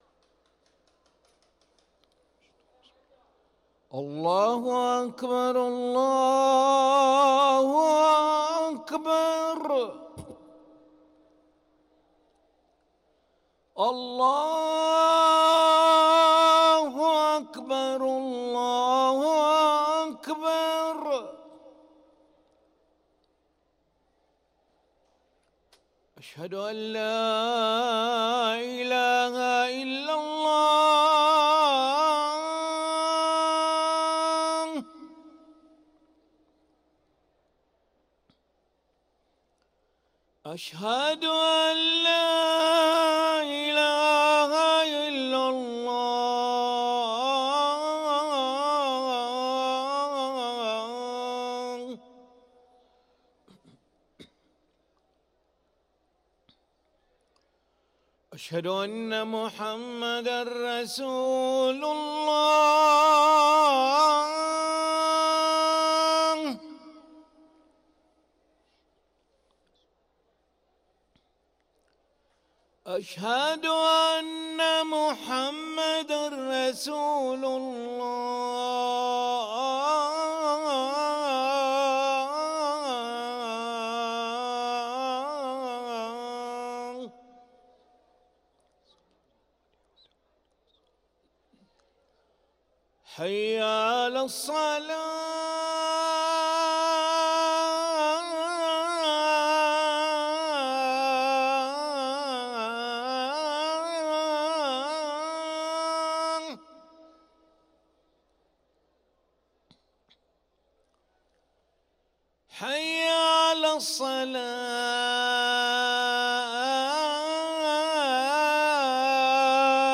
أذان العشاء للمؤذن علي ملا الخميس 4 ذو الحجة 1444هـ > ١٤٤٤ 🕋 > ركن الأذان 🕋 > المزيد - تلاوات الحرمين